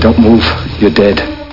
Amiga 8-bit Sampled Voice
1 channel
fire.mp3